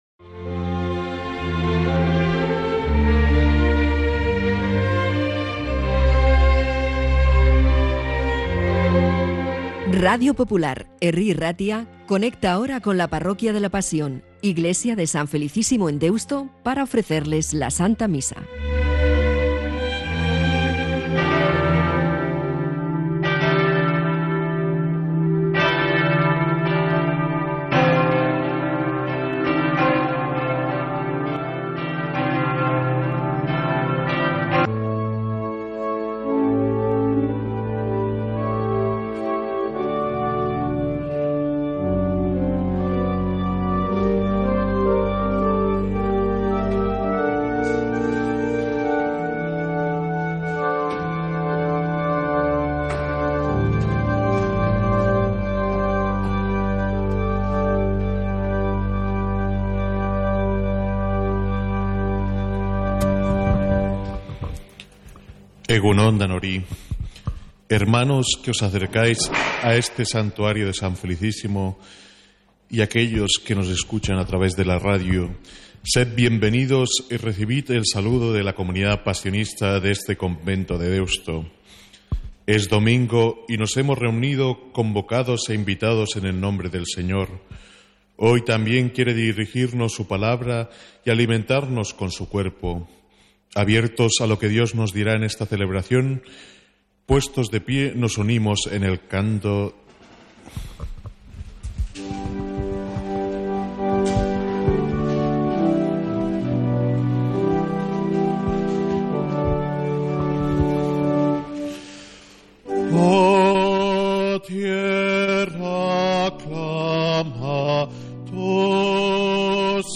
Santa Misa desde San Felicísimo en Deusto, domingo 16 de febrero